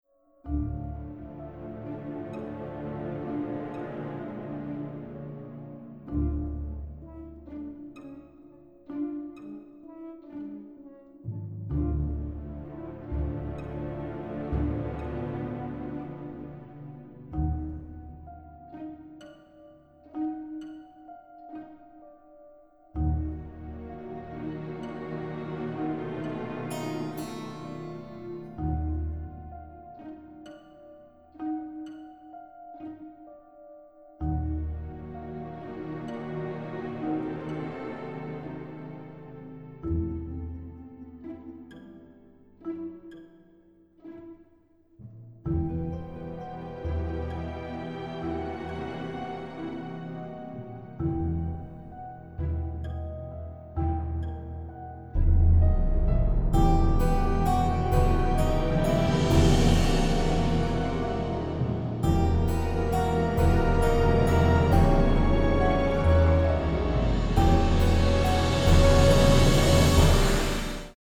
wit, slapstick, mystery and suspense with a classy touch